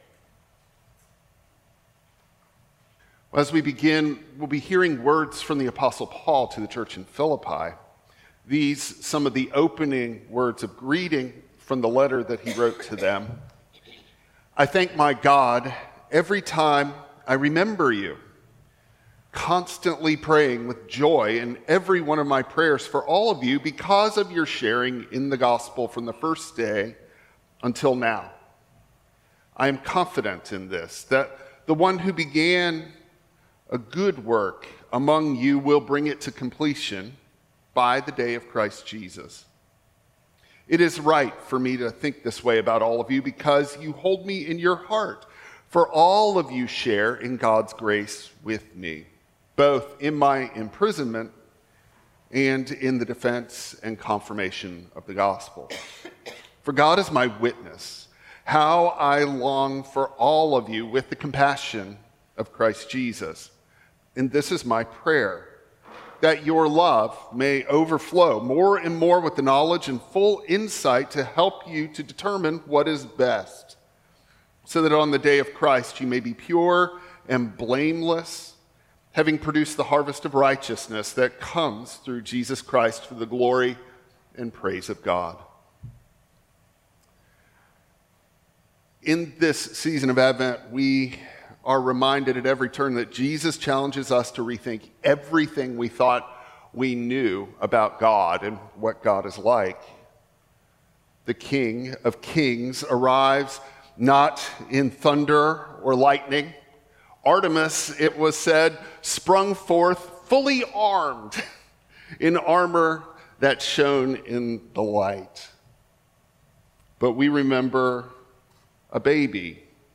Luke 1:68-79 Service Type: Traditional Service Peace is a light making room in the dark.
Dec-8-Sermon.mp3